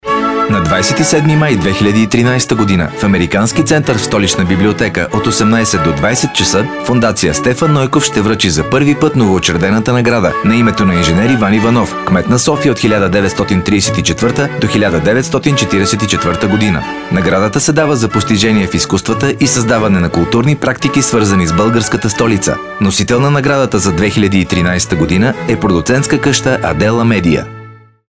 Съобщение за наградата по национално радио “Дарик”